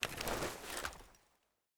Divergent / mods / Spas-12 Reanimation / gamedata / sounds / weapons / spas / close.ogg